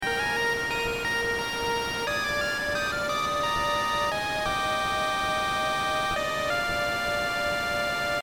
The was the map theme for the sea-world of a retro-style game I was working on.